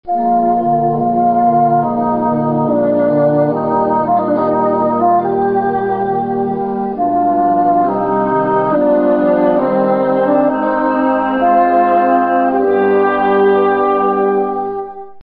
Le Basson
basson_chambre.mp3